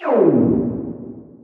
Techmino/media/effect/chiptune/fail.ogg at beff0c9d991e89c7ce3d02b5f99a879a052d4d3e
fail.ogg